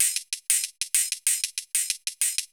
Index of /musicradar/ultimate-hihat-samples/95bpm
UHH_ElectroHatA_95-01.wav